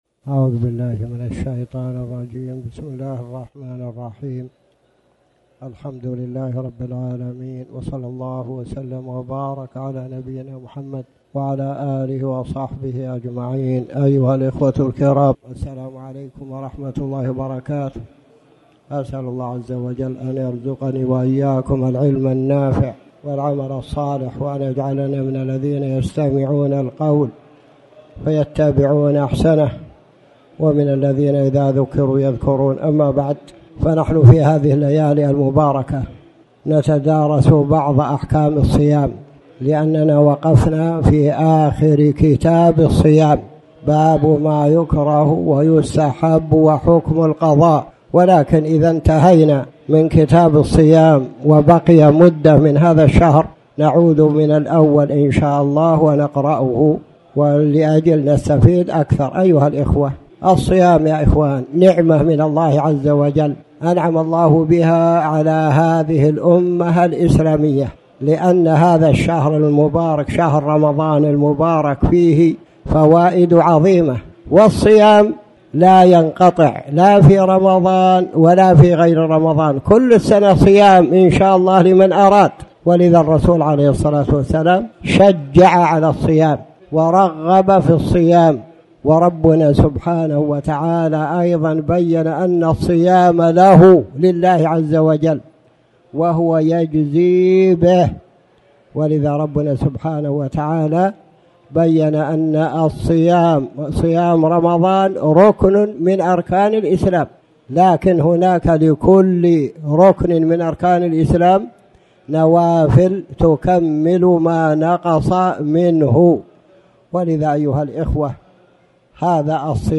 تاريخ النشر ٥ شعبان ١٤٣٩ هـ المكان: المسجد الحرام الشيخ